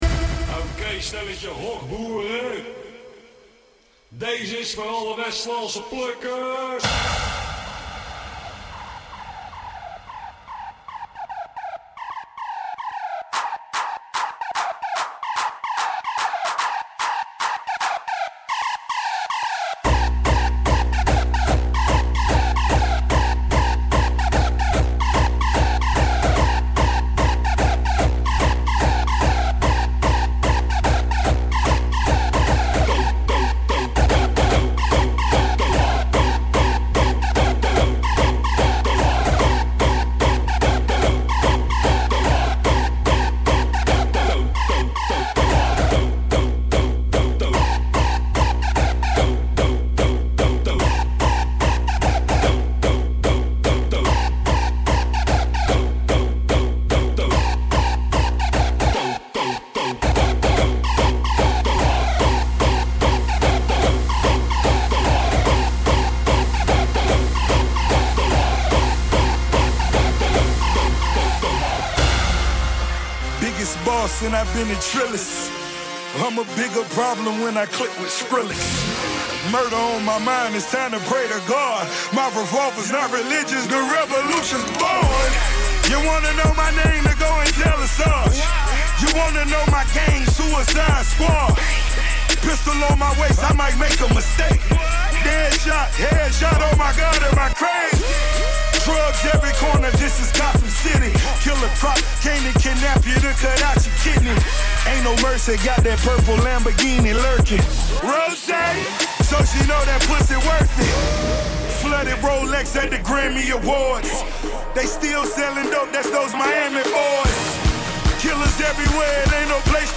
Live 19/8/16 deel 3